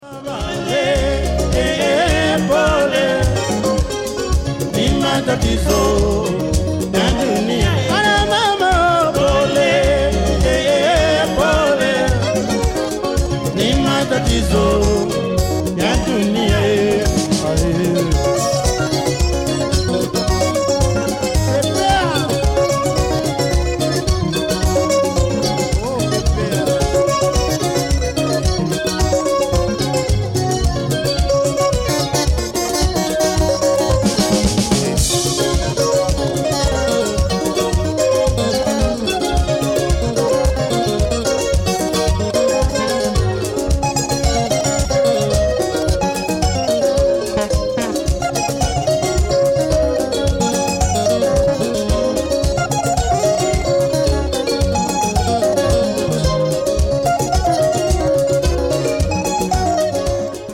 recorded this album in Holland in 1991
extra guitar and percussion being added later